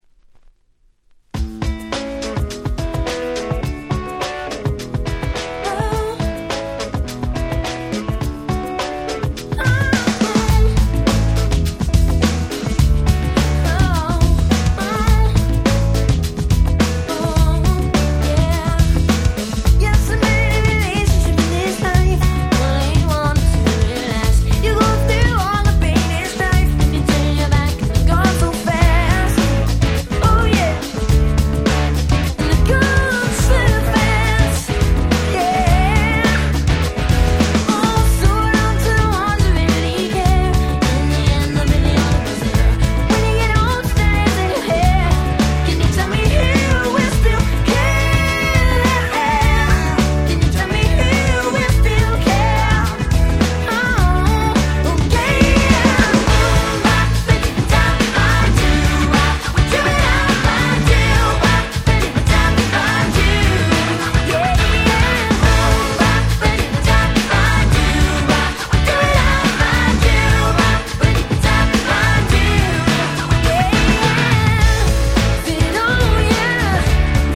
97' 世界的大ヒットPops !!